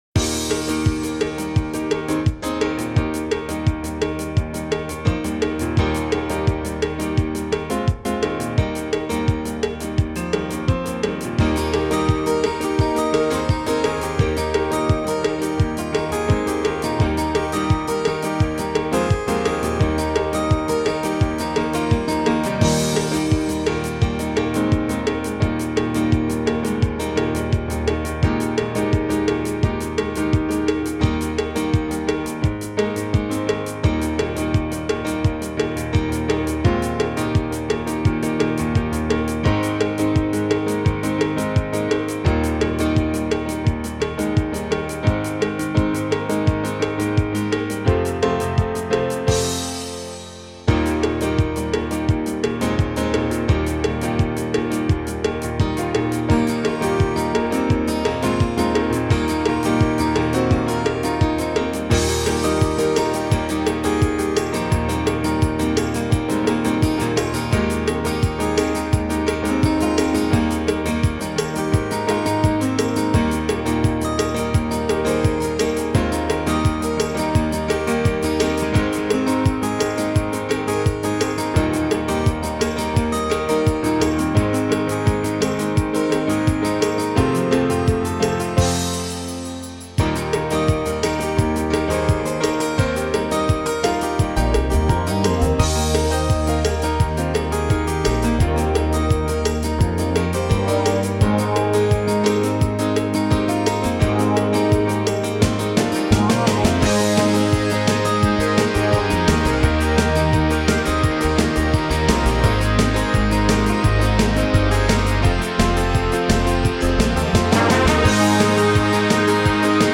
Übungsaufnahmen - Langeweile
Langeweile (Playback)
Langeweile__6_Playback.mp3